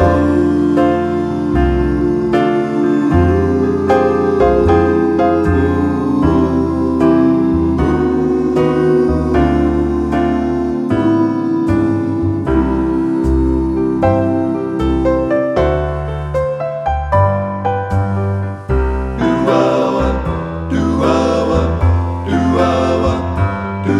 no Backing Vocals Duets 2:56 Buy £1.50